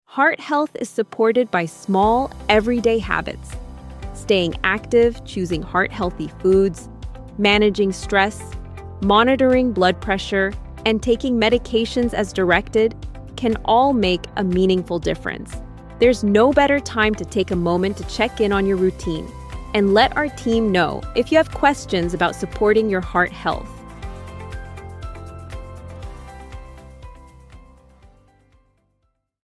IVR Recordings